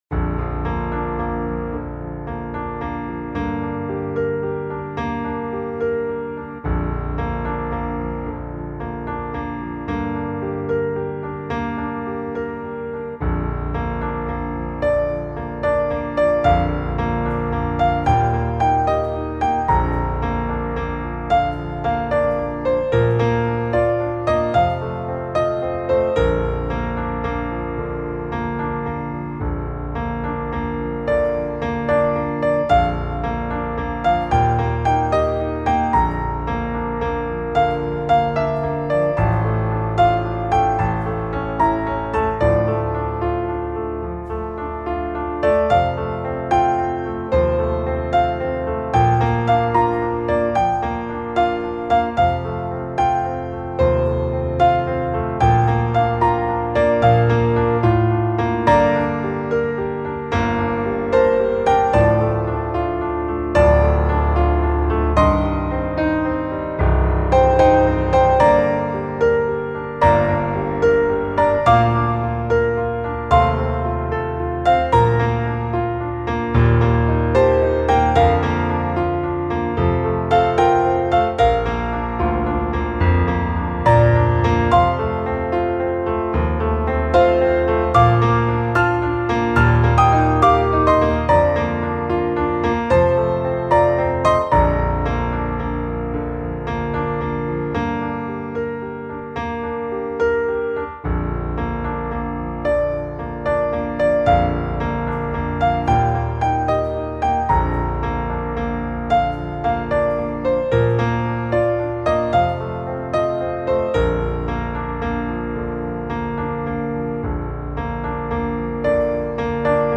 Piano Duet - Early Intermediate